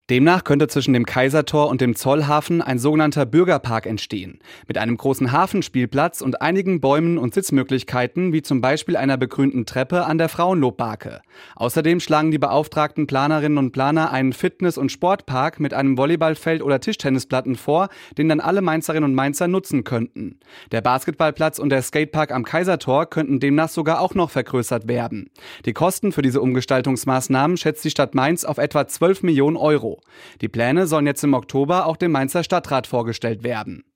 Umweltdezernentin Janina Steinkrüger